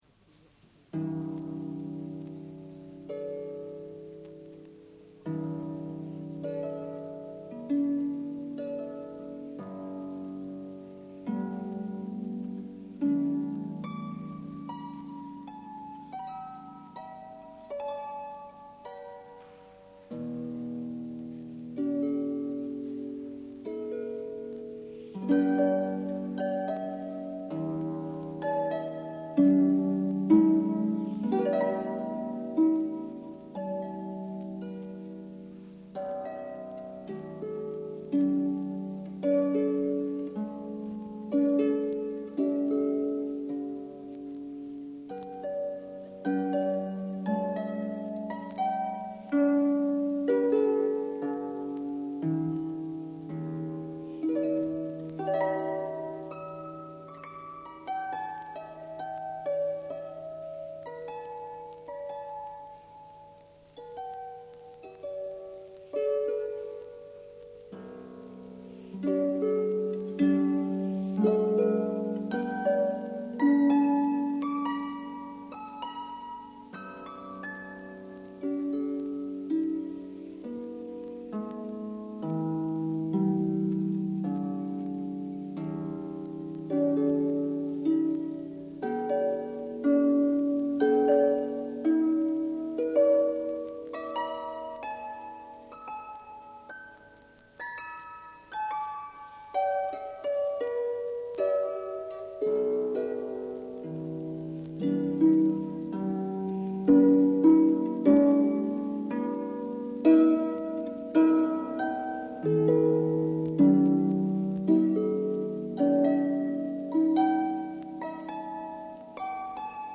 Fedrigotti ha poi scritto qualche brano per arpa che Cecilia Chailly ha eseguito:
Improvvisazione per arpa (1988)
Improvvisazione per arpa (1988).mp3